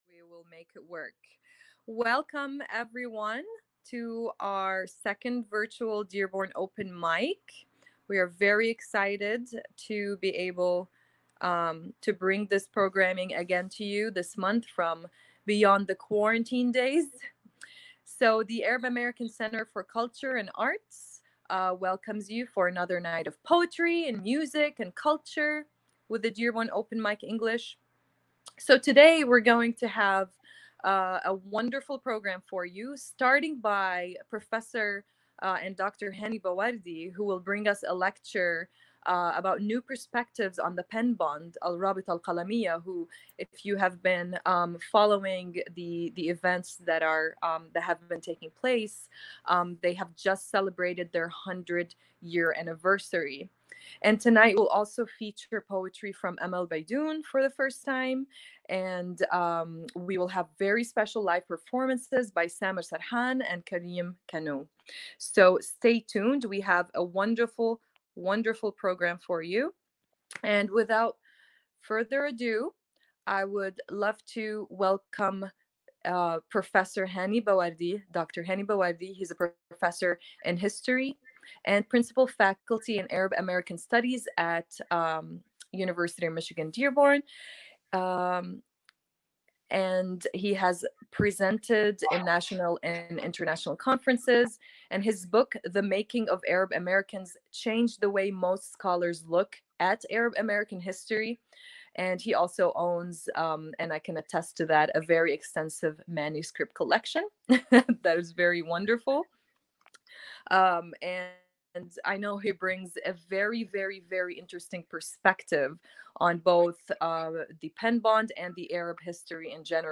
Other poets will participate in the open forum.
Arab American Center for Culture and Arts invite you for another night of poetry, music, and culture with the Dearborn Open Mic English (DOME) at 10PM on the third Wednesday of each month.